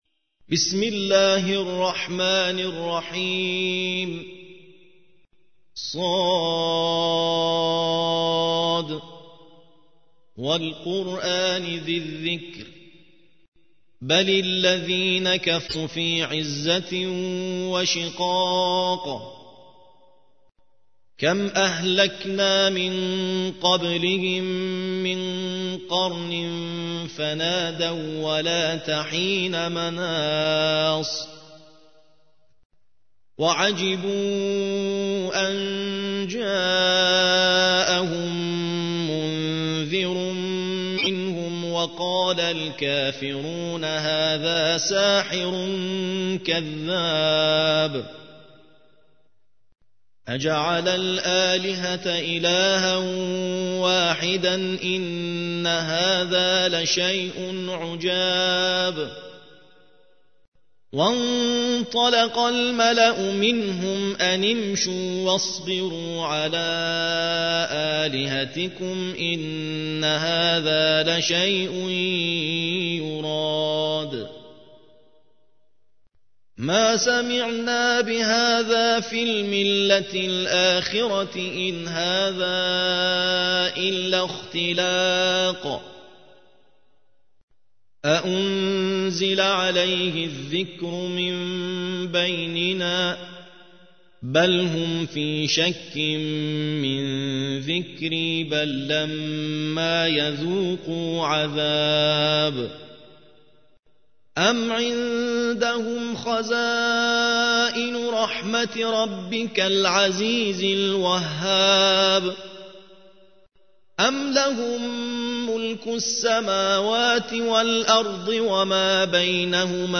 ترتیل سوره ص